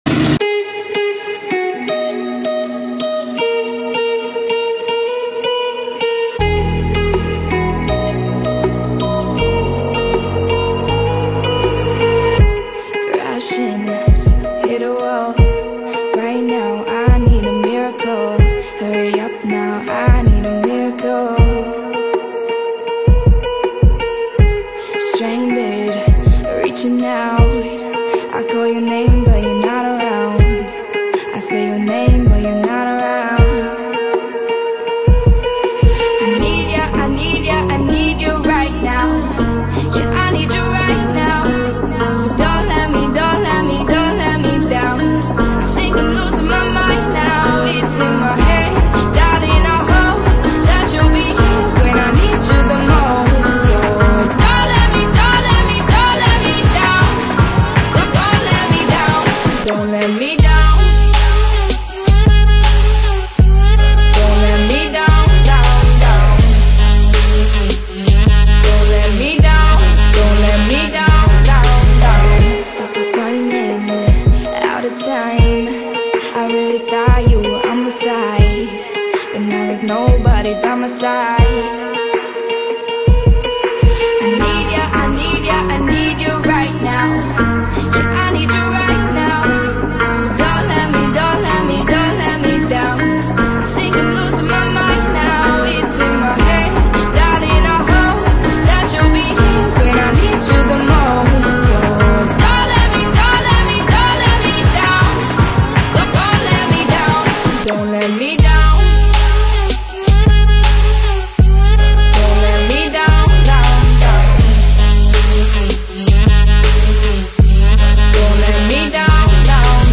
the American electronic DJ and production duo
The result was a song where vulnerability meets euphoria.
provided the emotive lead vocals.